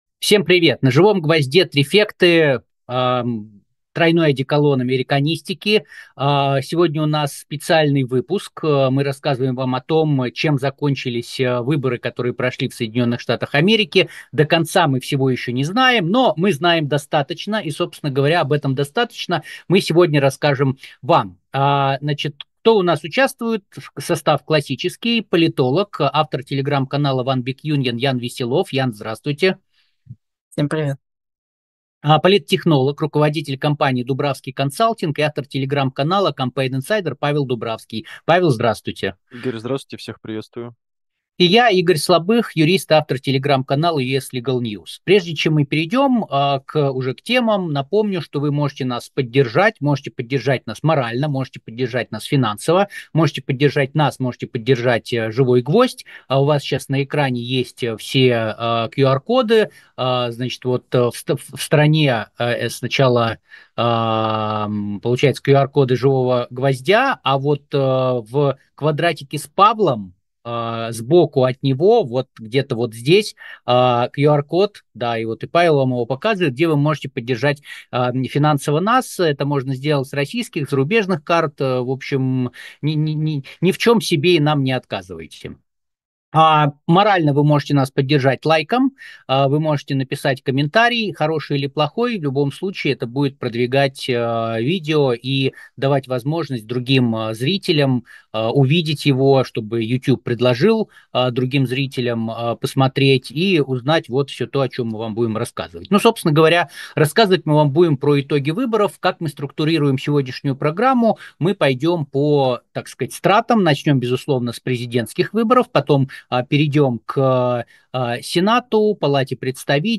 «Трифекты»: Итоги выборов в США. Спецэфир